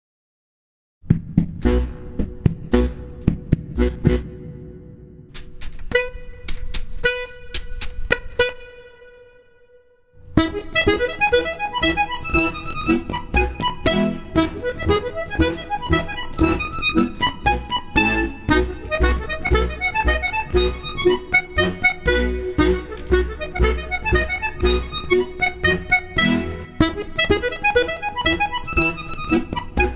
for accordion
modern compositions for accordion